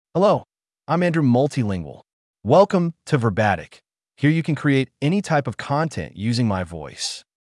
MaleEnglish (United States)
Andrew Multilingual is a male AI voice for English (United States).
Voice sample
Listen to Andrew Multilingual's male English voice.